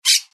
جلوه های صوتی
دانلود صدای پرنده 5 از ساعد نیوز با لینک مستقیم و کیفیت بالا